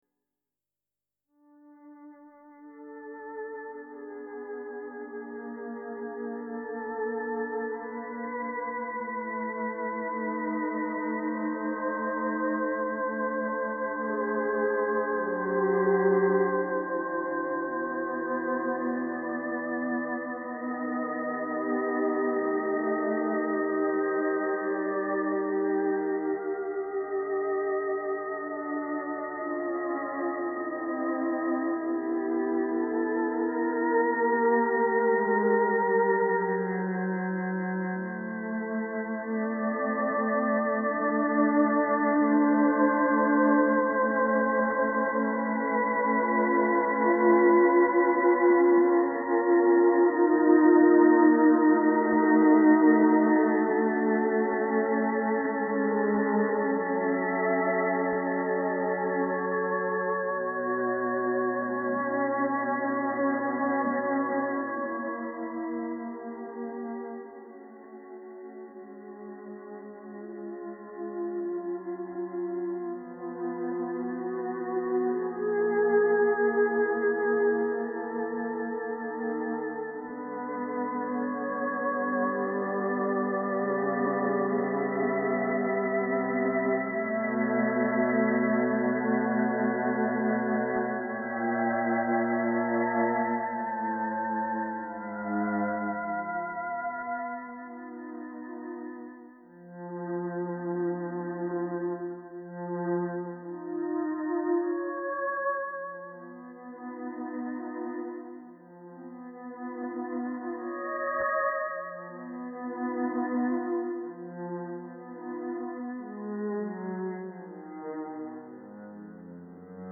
A private despair turns into a seemingly orchestral intimacy.